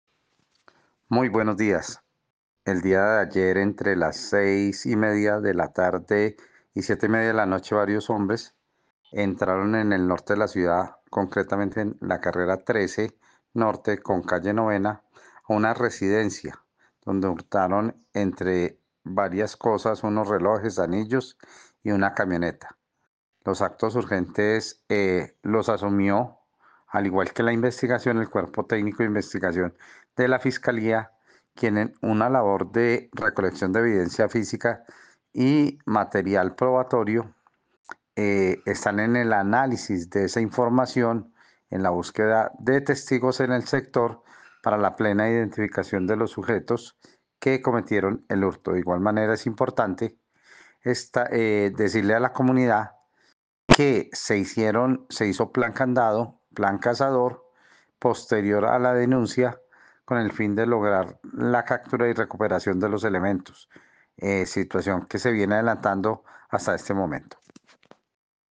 Secretario de Gobierno de Armenia sobre el caso de hurto